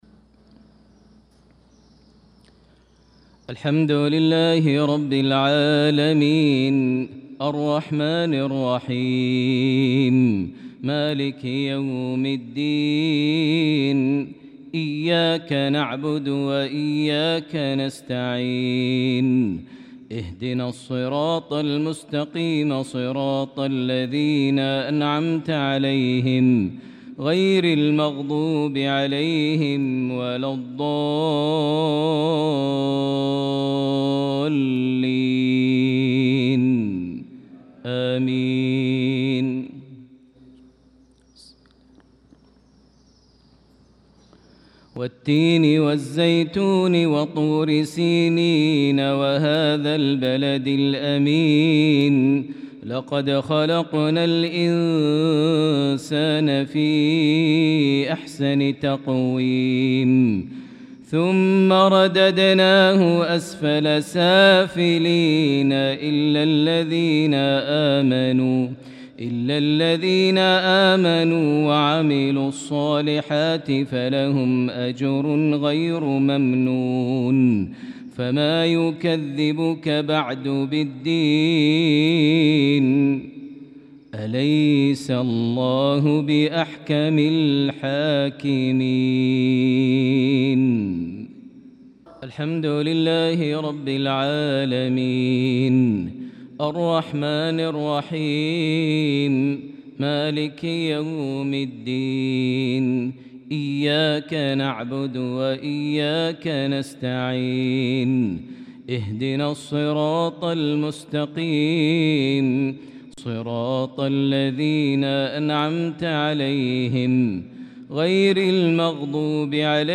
صلاة المغرب للقارئ ماهر المعيقلي 19 شوال 1445 هـ
تِلَاوَات الْحَرَمَيْن .